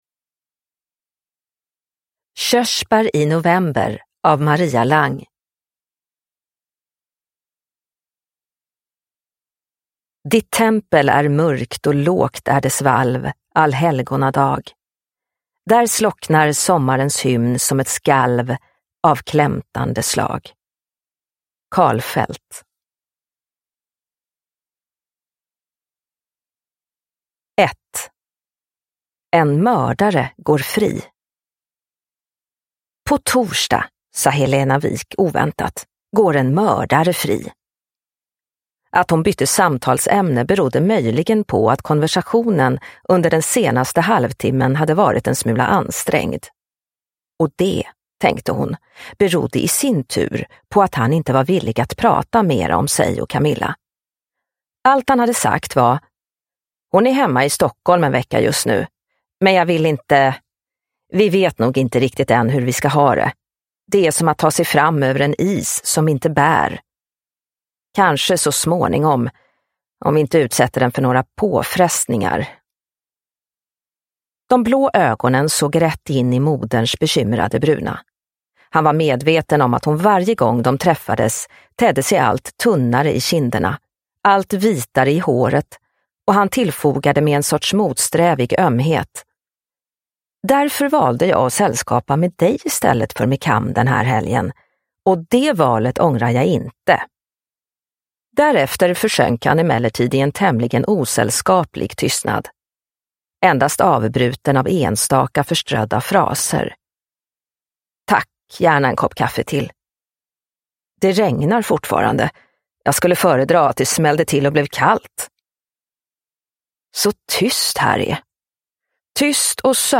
Körsbär i november – Ljudbok – Laddas ner